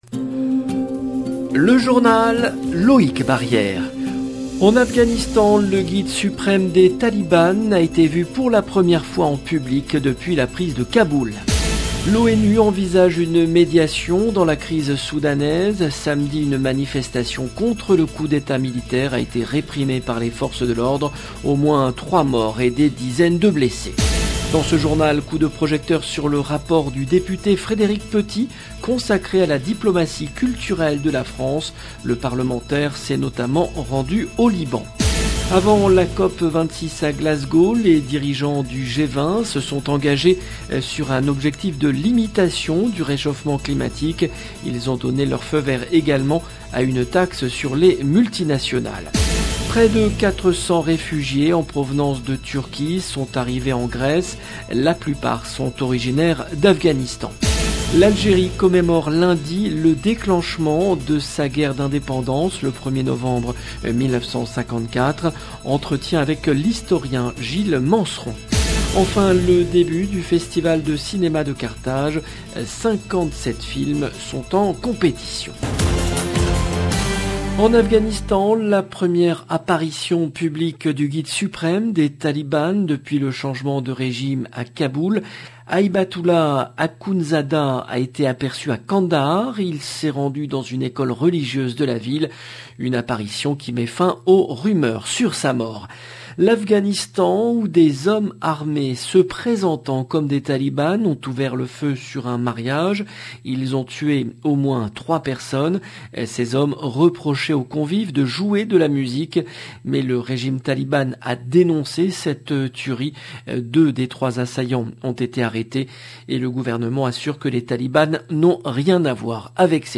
18 min 11 sec LE JOURNAL DU SOIR EN LANGUE FRANCAISE DU 31/10/21 LB JOURNAL EN LANGUE FRANÇAISE En Afghanistan, le guide suprême des taliban a été vu pour la première fois en public depuis la prise de Kaboul. L’ONU envisage une médiation dans la crise soudanaise.